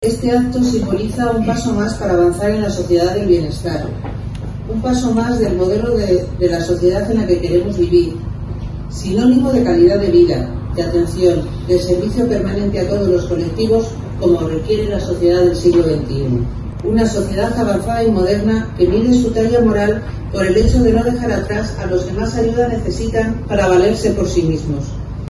El pasado 3 de octubre se produjo el acto de colocación de la primera piedra de un nuevo gran edificio que se convertirá en el mayor centro de atención a la discapacidad de toda la región.
Por su parte, la subdelegada del Gobierno,